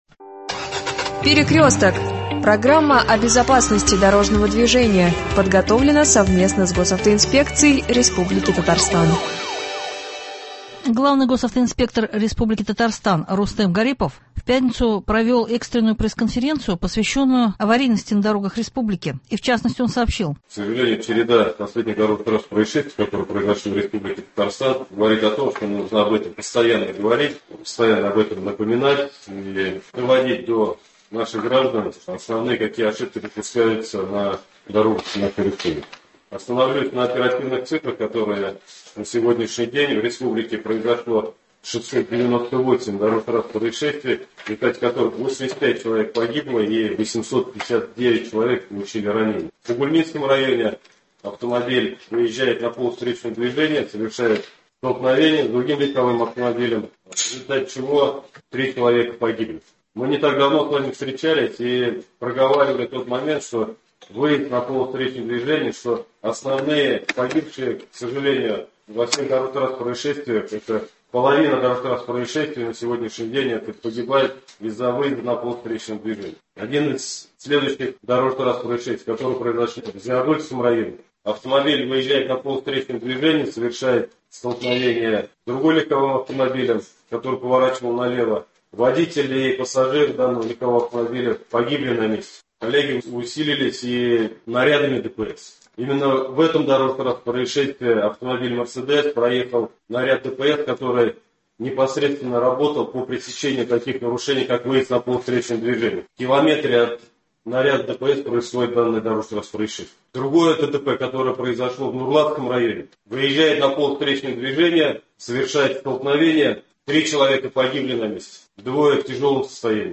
Пресс- конференция главного госавтоинспектора РТ Р. Гарипова.